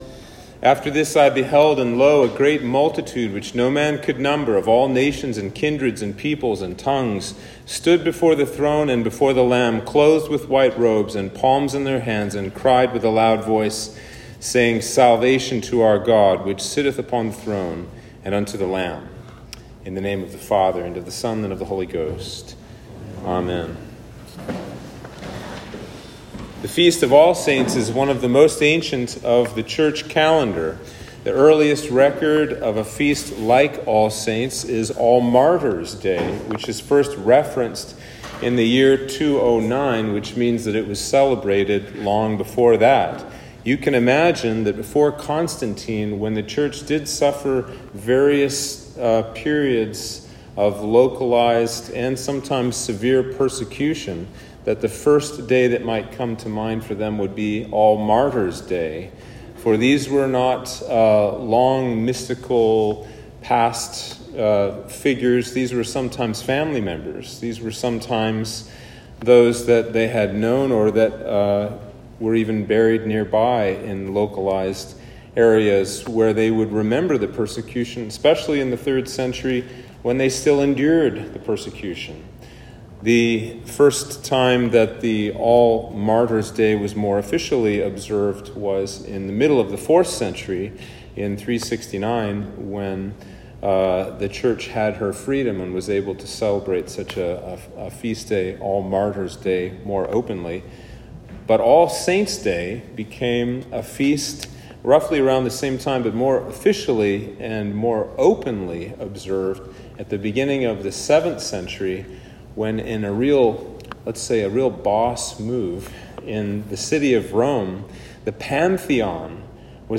Sermon for All Saints' Day